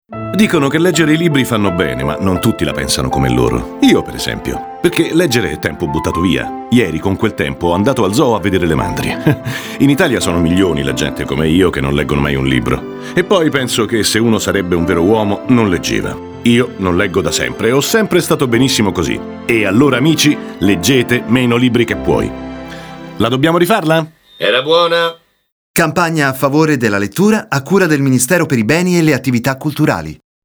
Spot radio “Invito alla lettura”